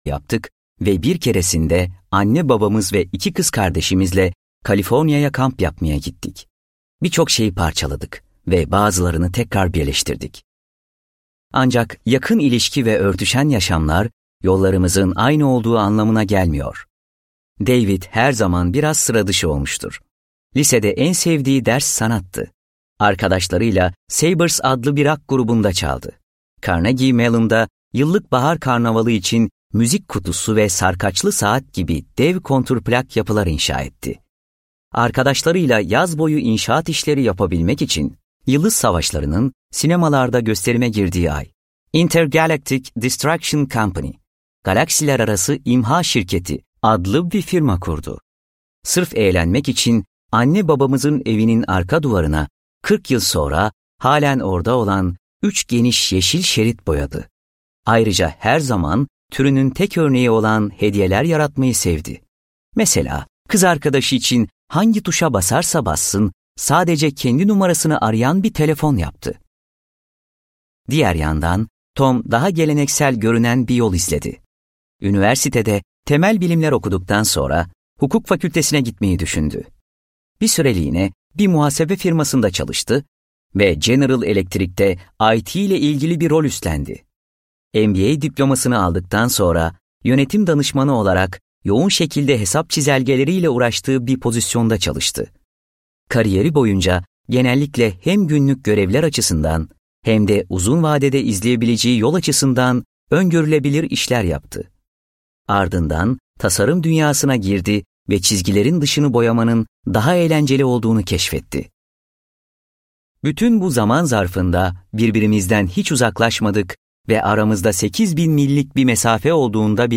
Yaratıcı Özgüven - Seslenen Kitap